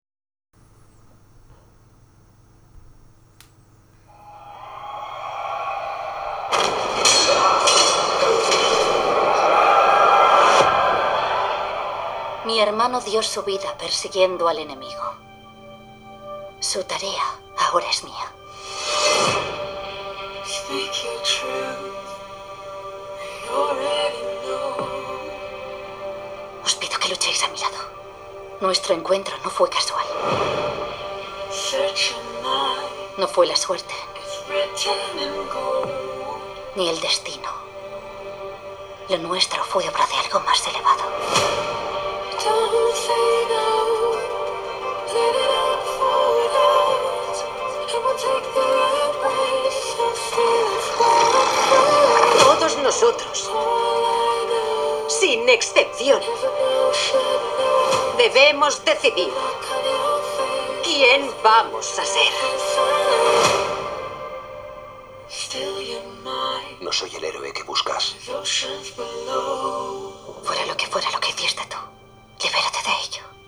Sobre el sistema de sonido se menciona poco en la descripción del MSI CreatorPro Z16P, pero cuenta con una configuración con 4 altavoces Dyanudio y Hi-Res, todos ellos mirando hacia abajo en las esquinas frontales.
En consecuencia, el audio se escucha descafeinado, solo con presencia de medios y agudos, al comenzar a responder en frecuencias superiores a 90 Hz aproximadamente. La sensación envolvente si es correcta, existiendo claridad en la reproducción pese a no tener un gran volumen máximo.